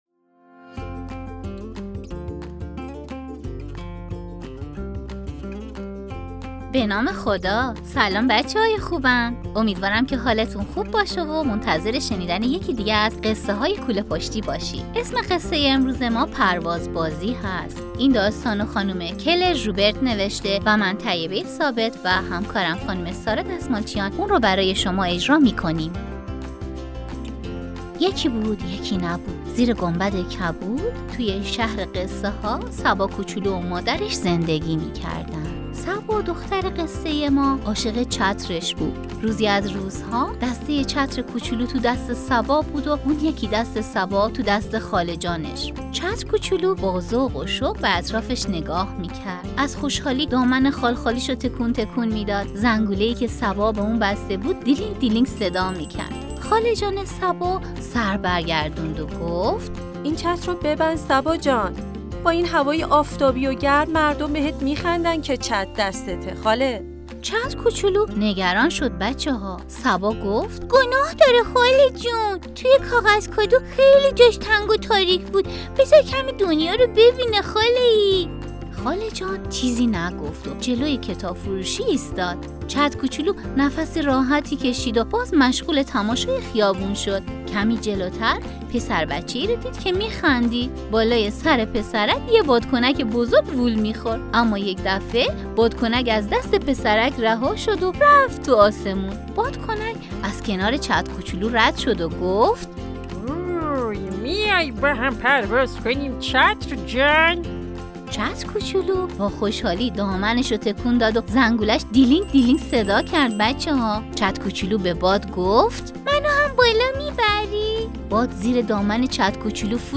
پادکست داستانی | «پرواز بازی» اثر «کلر ژوبرت»